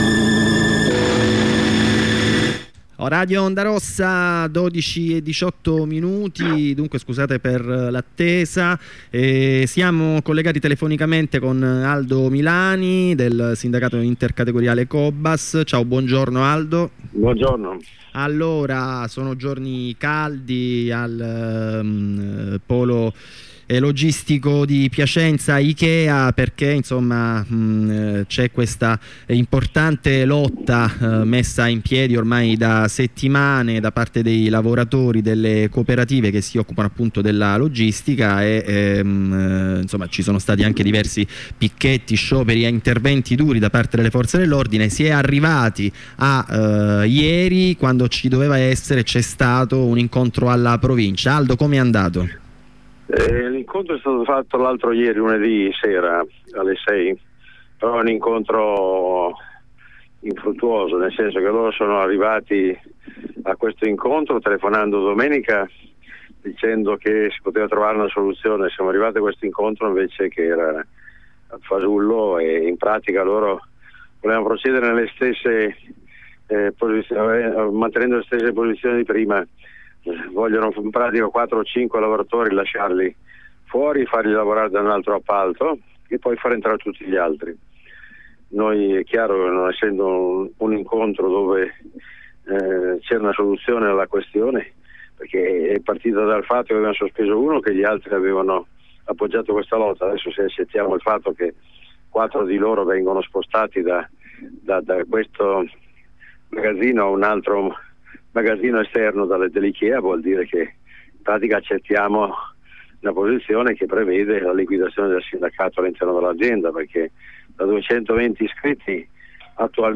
Corrispondenza con un insegnante della scuola primaria; Corrispondenza con un lavoratore Sda delegato Si Cobas; Corrispondenza con un compagno del coordinamento romano del Si Cobas.